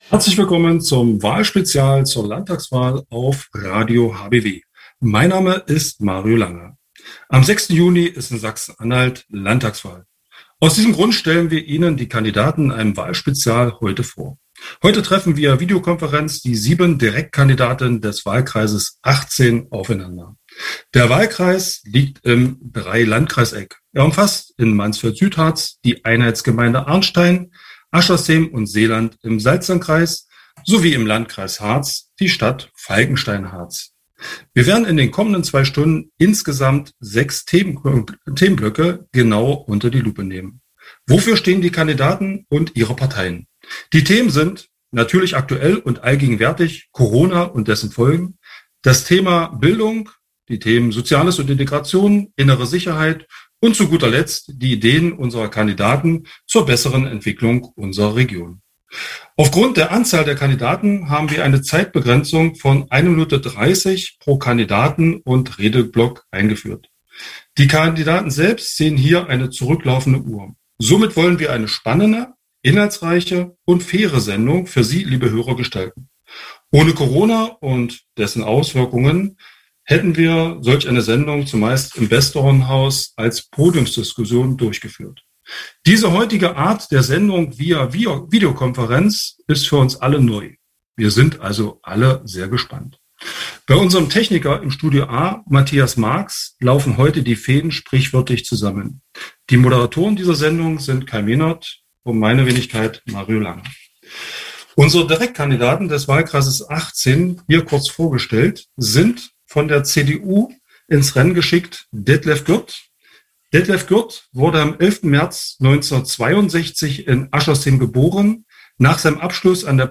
selbstverständlich pandemiegerecht per Video-Schalte.